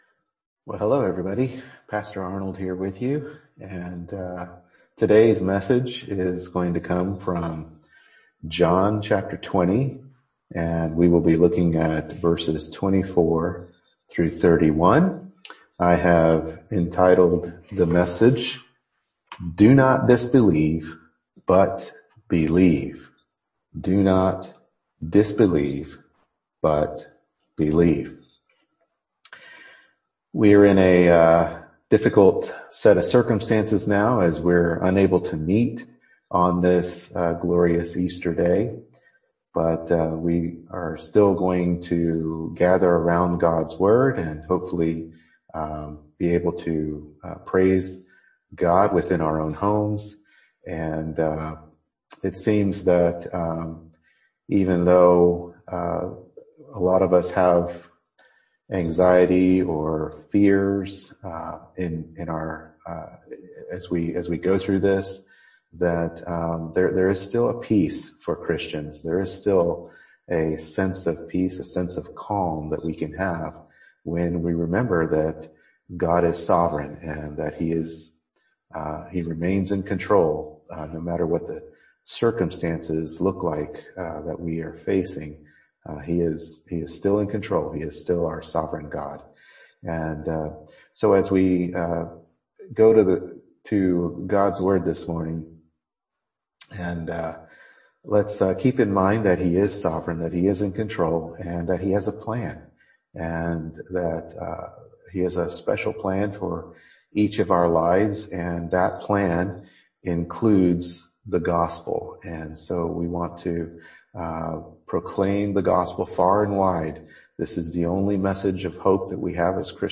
John 20:24-31 Service Type: Morning Worship Service John 20:24-31 “Do Not Disbelieve